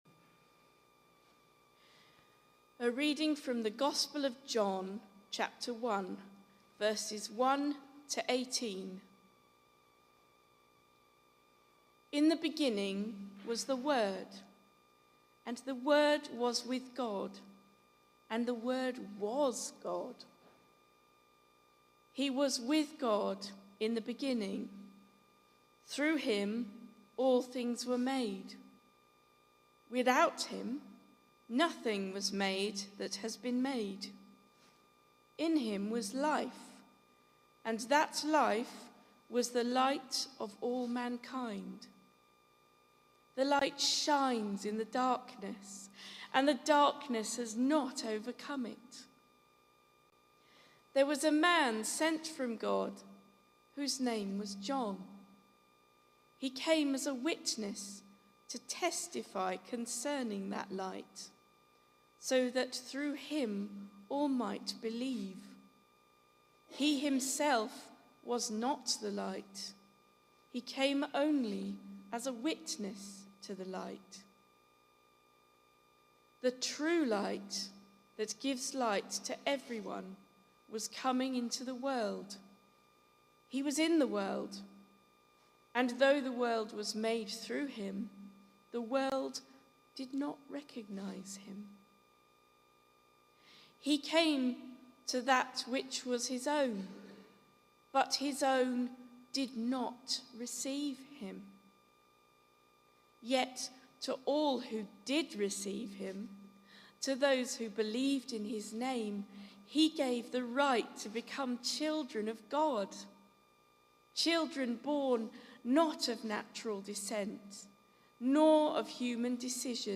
The Word made Flesh - my reading of John 1:1-18 at a service on 3 November 2024
This was part of a service for All Saints, celebrating various disciples from the Bible